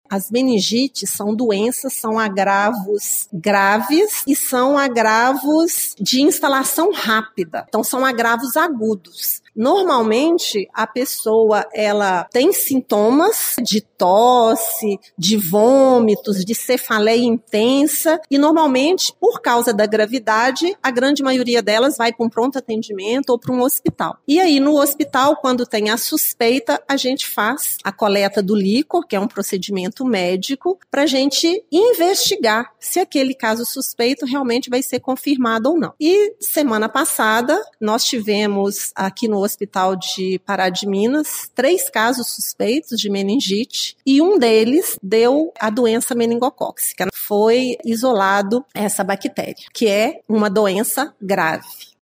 A informação foi repassada ontem durante coletiva de imprensa